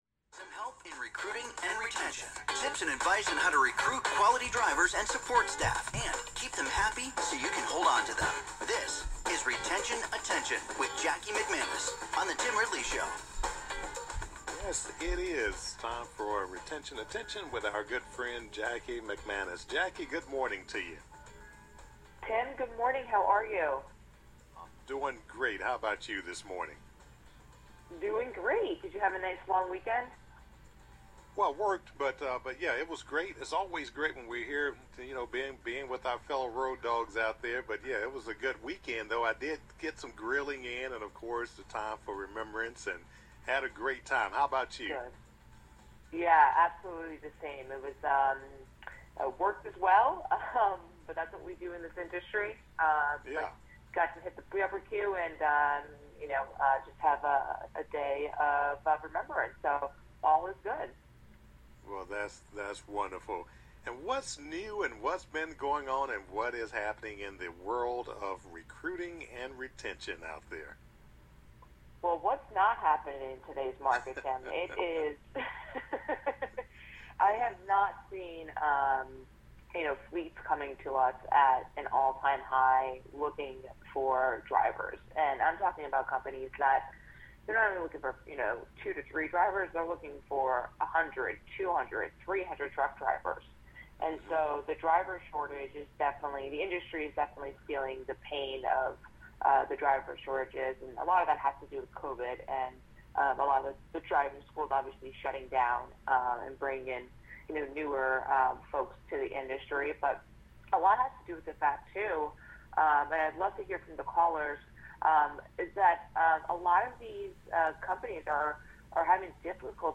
Interestingly, all three call-ins discussed the same thing: communication quality. One of the largest communication roadblocks is the expectations given during the hiring process.